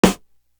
Worst To Worst Snare.wav